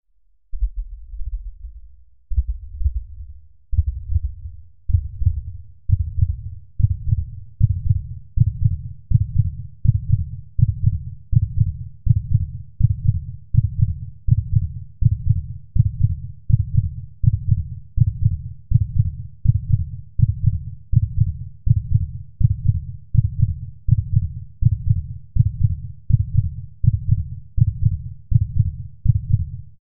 دانلود صدای قلب مادر برای نوزاد از ساعد نیوز با لینک مستقیم و کیفیت بالا
جلوه های صوتی
برچسب: دانلود آهنگ های افکت صوتی انسان و موجودات زنده دانلود آلبوم صدای ضربان قلب انسان از افکت صوتی انسان و موجودات زنده